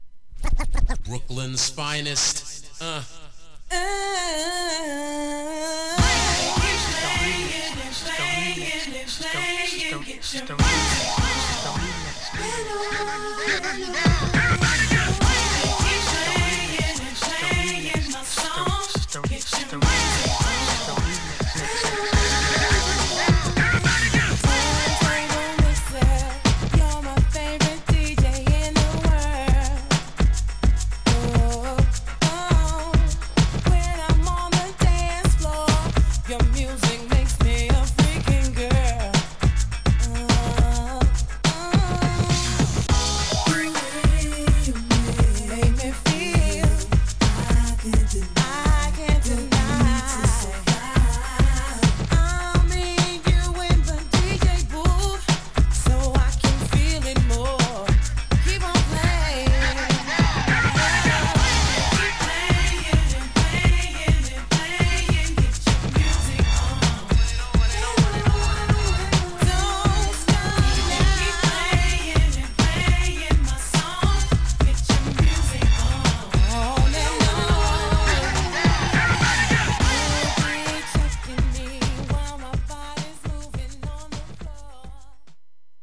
Main With Rap